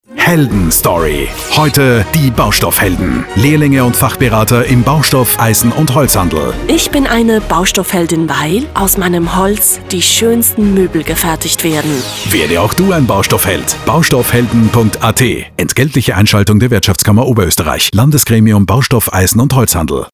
In anregenden Werbespots sollen Jugendliche für den Lehrberuf des Händlers in unserem Gremium begeistert werden.